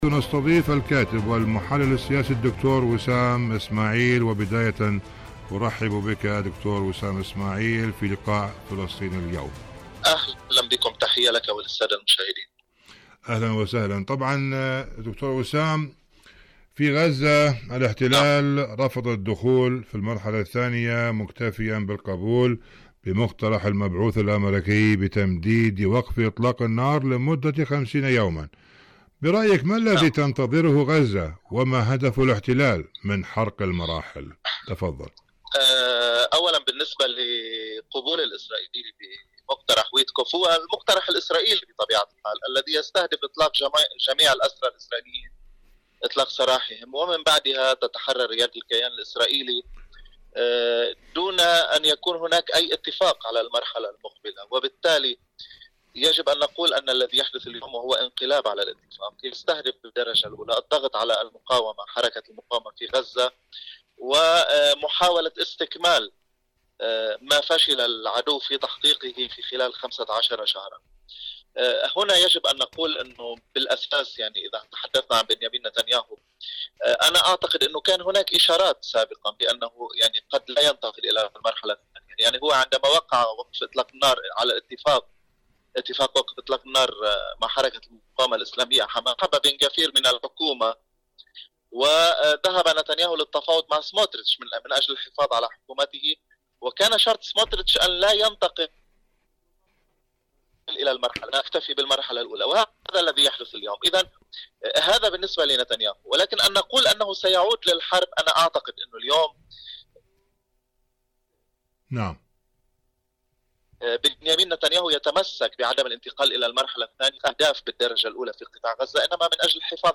إذاعة طهران- فلسطين اليوم: مقابلة إذاعية